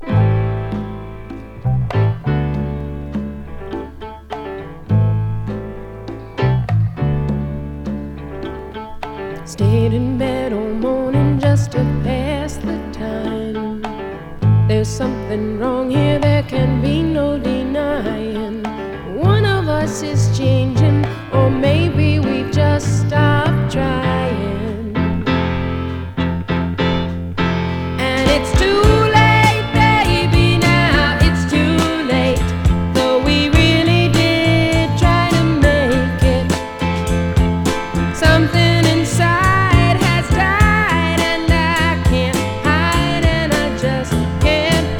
Pop, Rock, SSW　Netherlands　12inchレコード　33rpm　Stereo